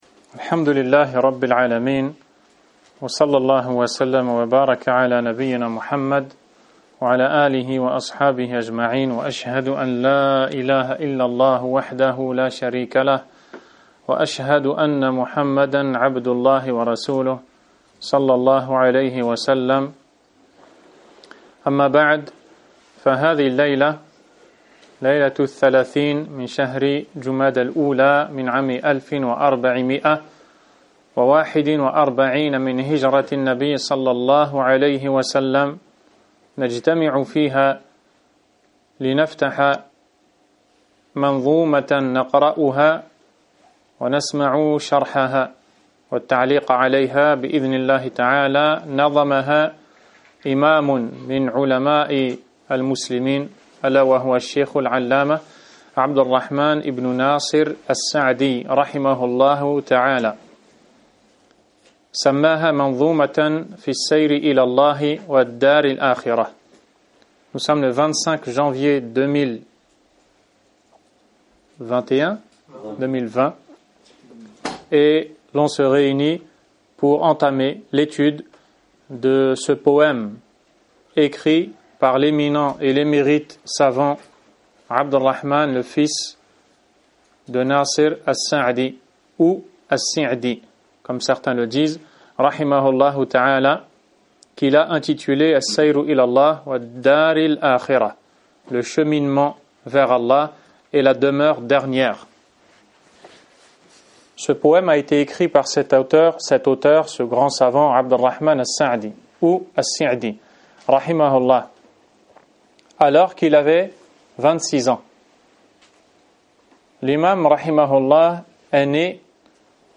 Cours 01 - Introduction sur le cheminement vers ALLAH